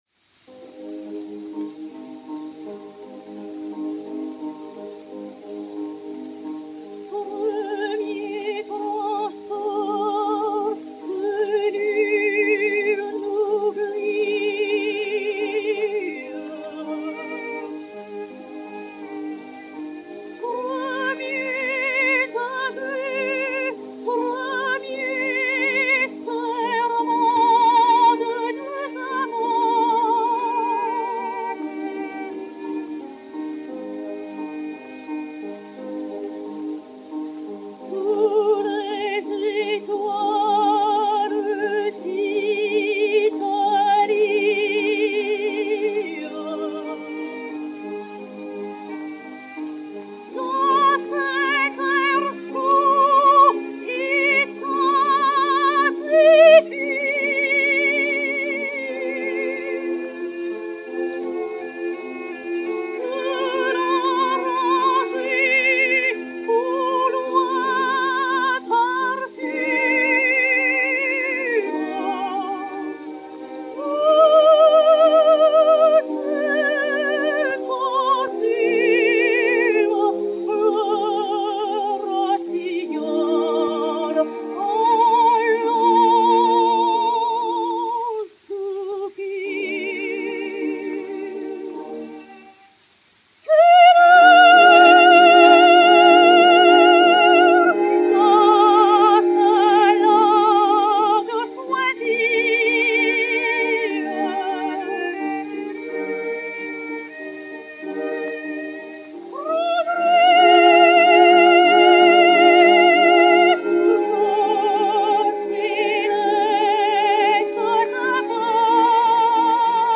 Berthe Auguez de Montalant et Orchestre
Disque Pour Gramophone 033106, mat 01100v, enr. à Paris le 15 juin 1909